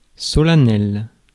Ääntäminen
Synonyymit pompeux Ääntäminen France: IPA: [sɔ.la.nɛl] Haettu sana löytyi näillä lähdekielillä: ranska Käännös Ääninäyte Adjektiivit 1. solemn US 2. formal US 3. ceremonious Suku: m .